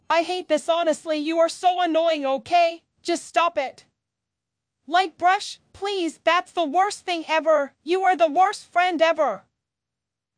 angry.wav